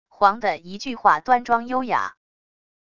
凰的一句话端庄优雅wav音频